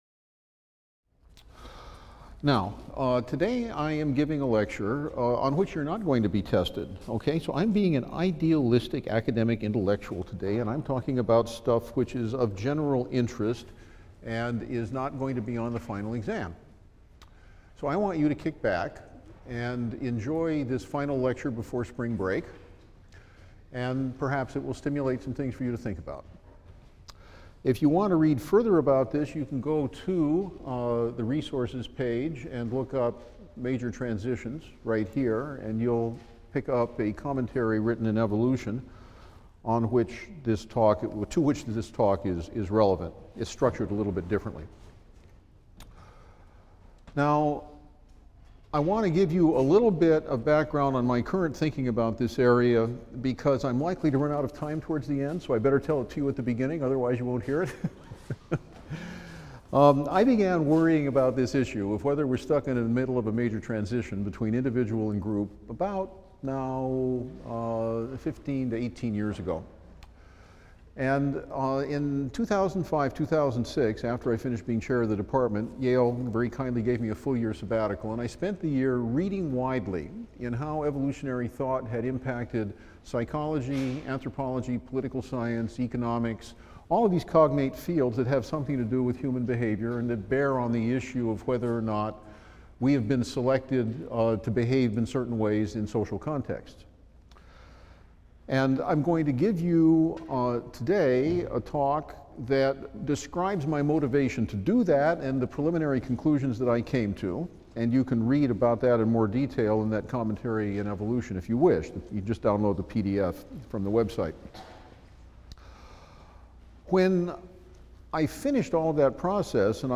E&EB 122 - Lecture 22 - The Impact of Evolutionary Thought on the Social Sciences | Open Yale Courses